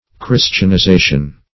\Chris`tian*i*za"tion\
christianization.mp3